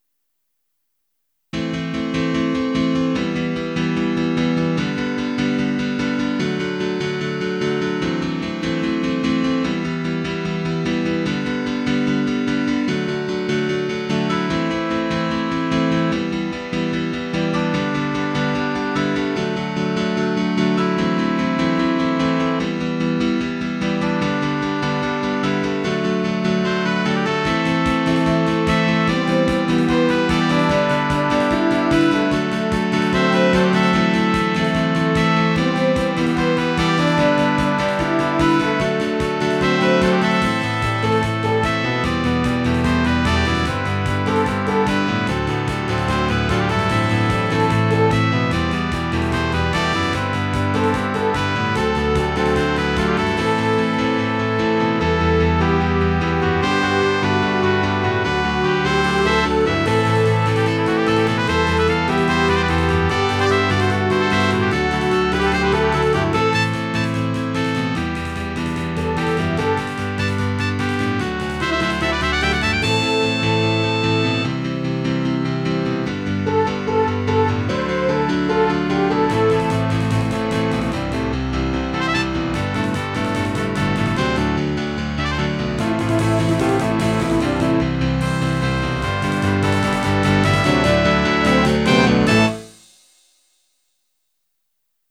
Title Moisterous! Opus # 137 Year 0000 Duration 00:01:35 Self-Rating 3 Description Let the spit valve sputter! mp3 download wav download Files: wav mp3 Tags: Piano, Brass, Percussion Plays: 1942 Likes: 0